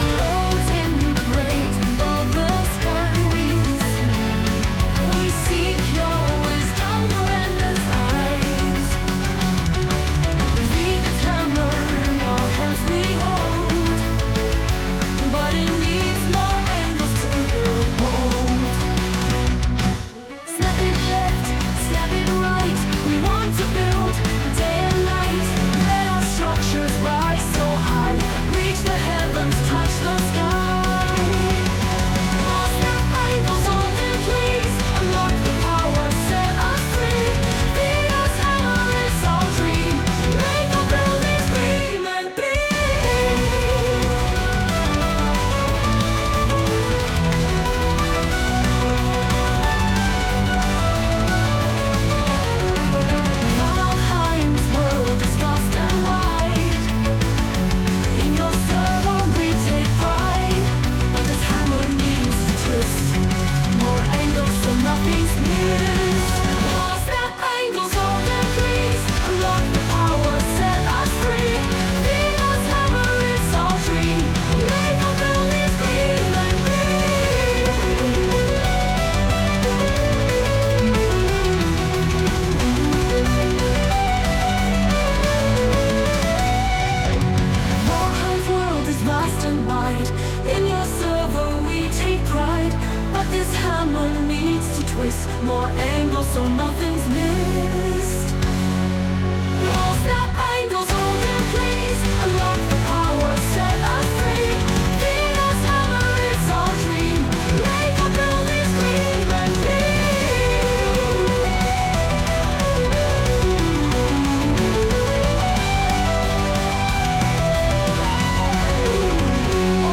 Oh and let's not forget the song he had AI make as part of the campaign to convince me.